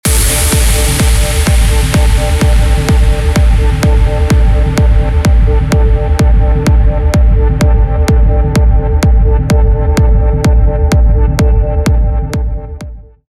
Старт с первого бита